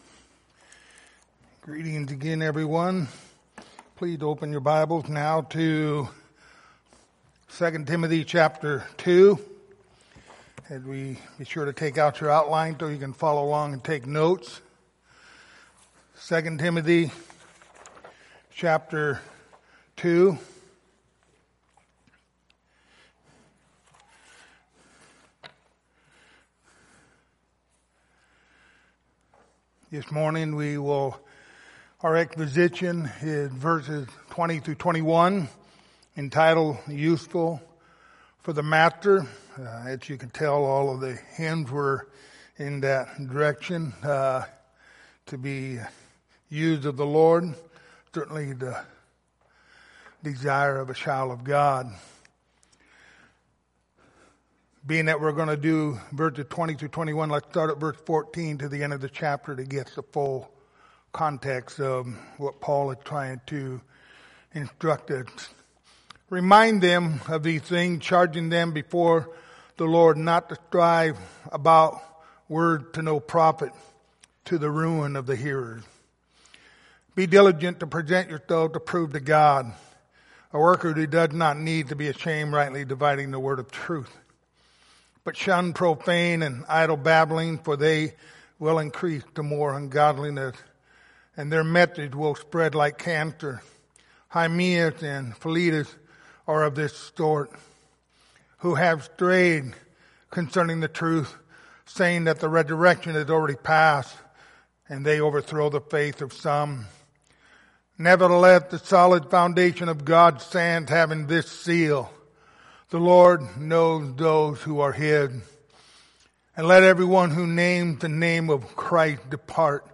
Pastoral Epistles Passage: 2 Timothy 2:20-21 Service Type: Sunday Morning Topics